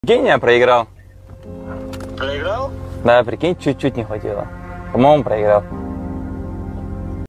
prikin ia proigral Meme Sound Effect